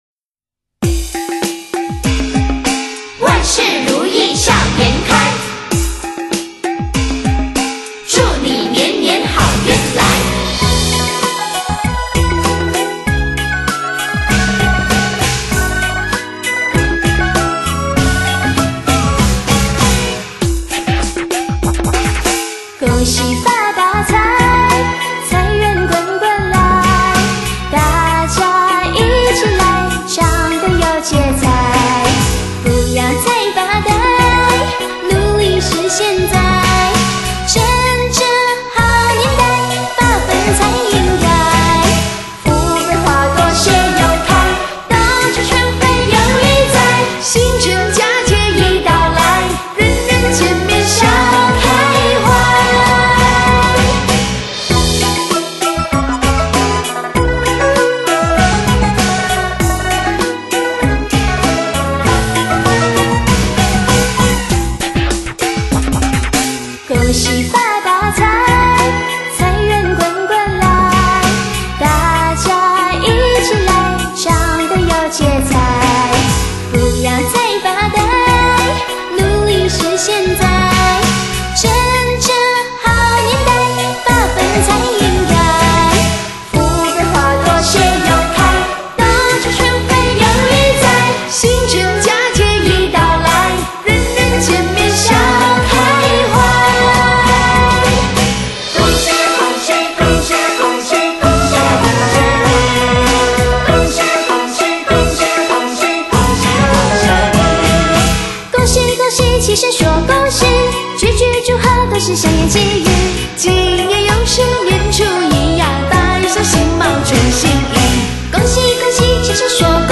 她们轻快的声音，翩翩的舞步，仿佛把春节美妙人间欢腾的气氛带给大家。